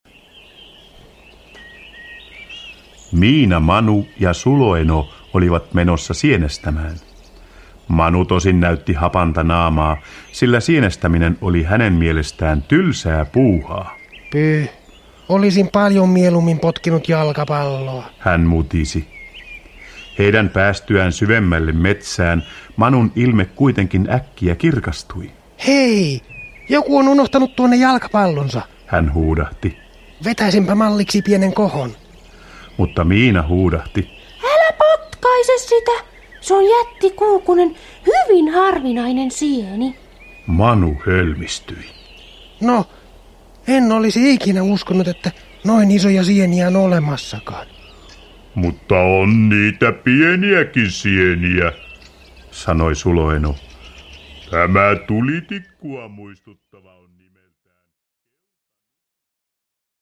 Miina ja Manu villisikojen valtakunnassa – Ljudbok – Laddas ner